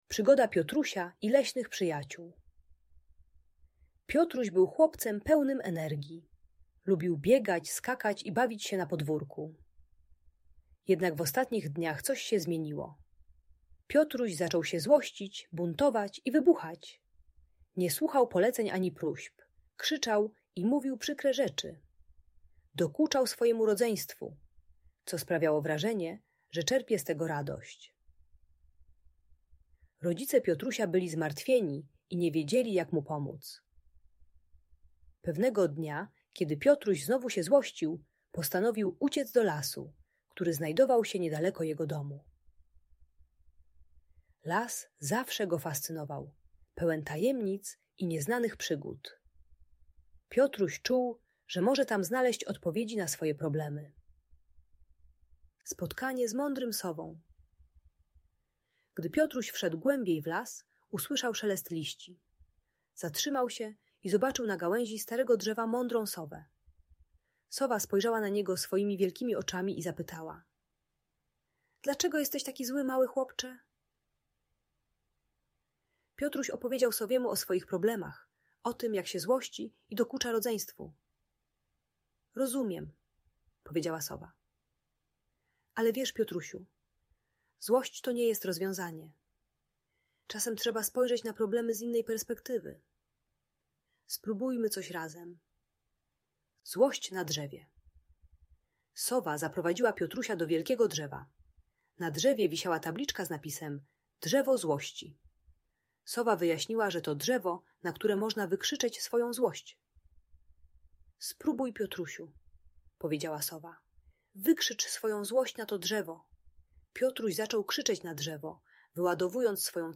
Przygoda Piotrusia i Leśnych Przyjaciół - story o emocjach - Audiobajka